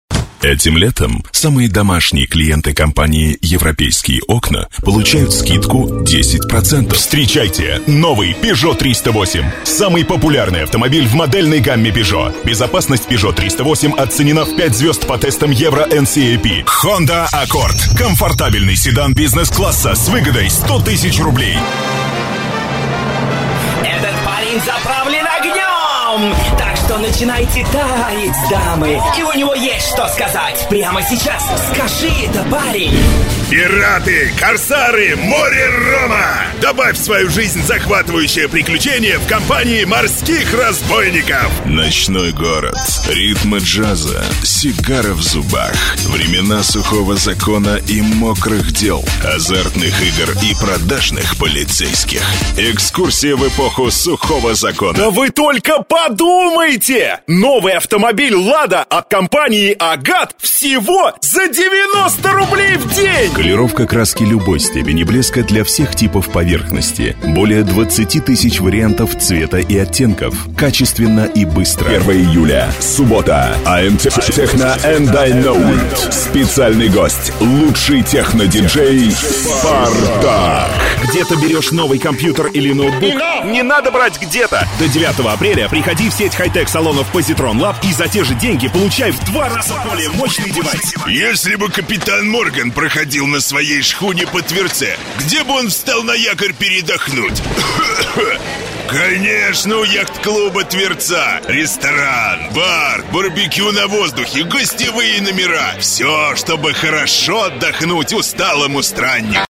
Тракт: Zoom H4n, шумоизолированное помещение.
Демо-запись №1 Скачать